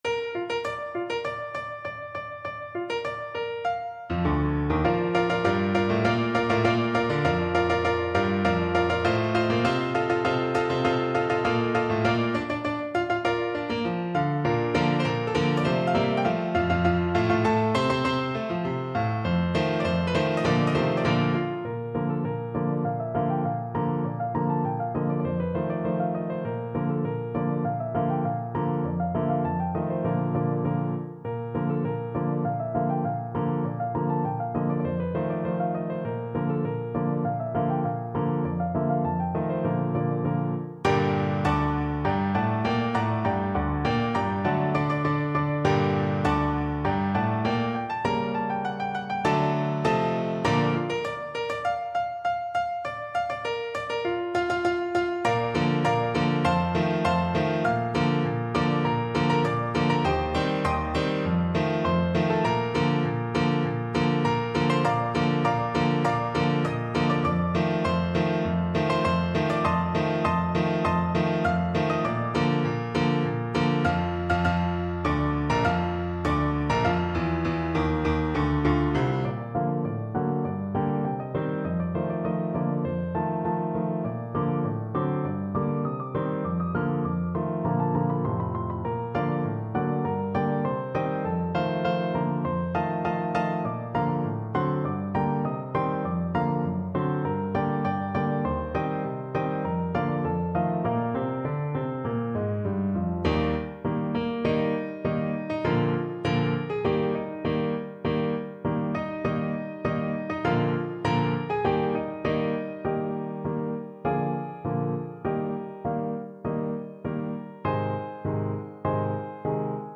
2/4 (View more 2/4 Music)
Arrangement for Piano solo
Piano  (View more Intermediate Piano Music)
Classical (View more Classical Piano Music)